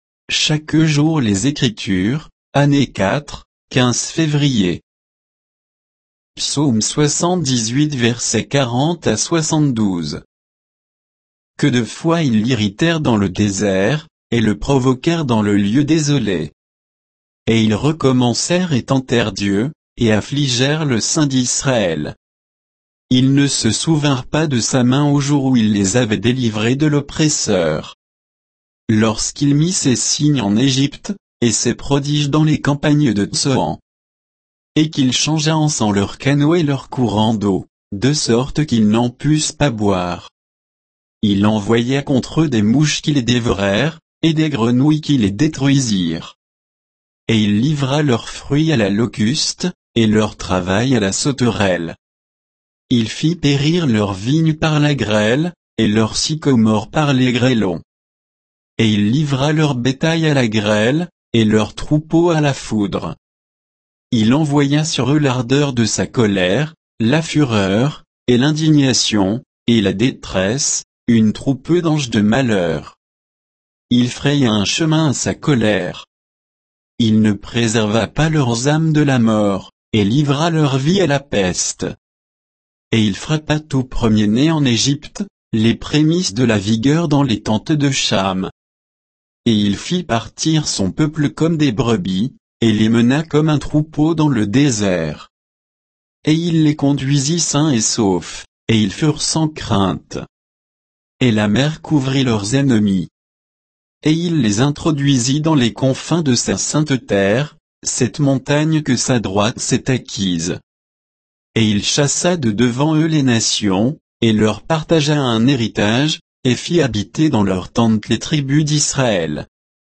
Méditation quoditienne de Chaque jour les Écritures sur Psaume 78